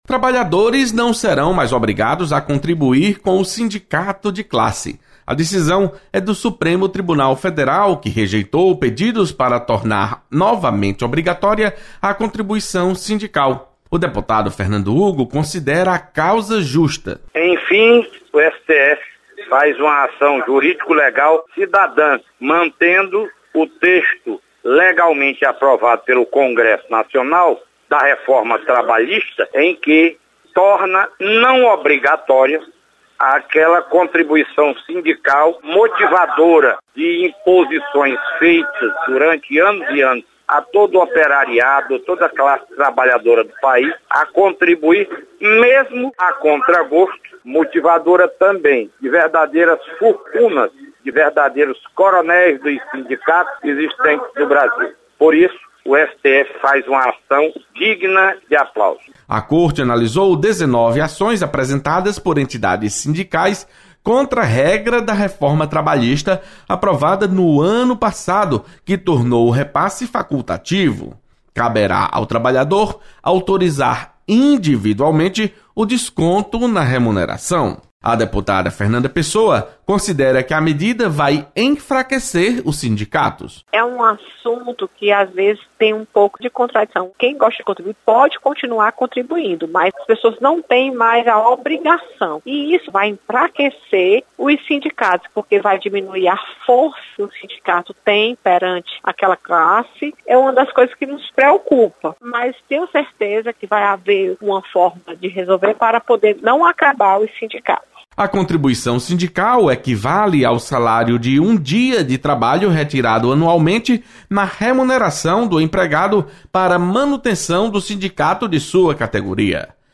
Deputados comentam sobre fim da obrigatoriedade da contribuição sindical.